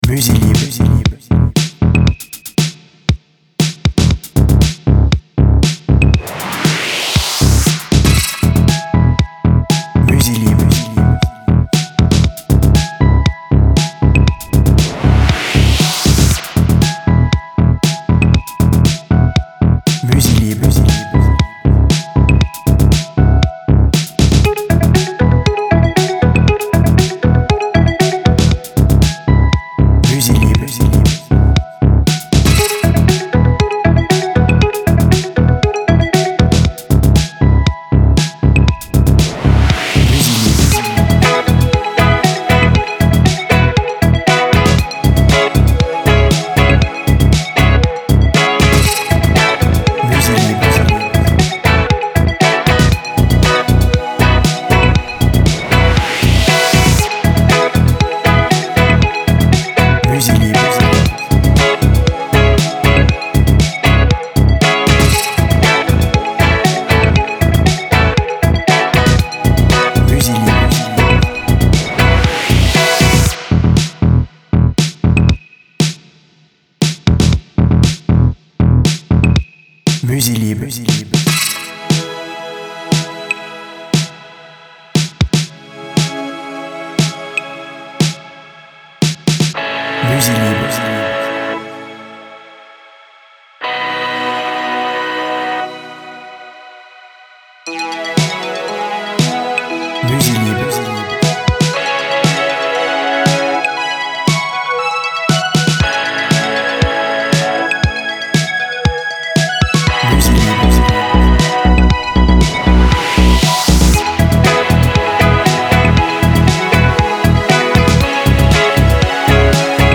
Musique joyeuse et festive, pop et electro.
BPM Rapide